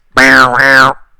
error.opus